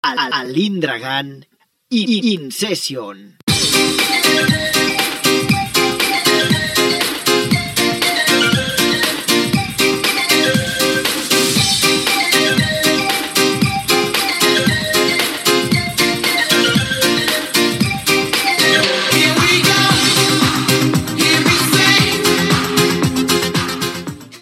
Nom del DJ i inici de la sessió de mescla musical.
Musical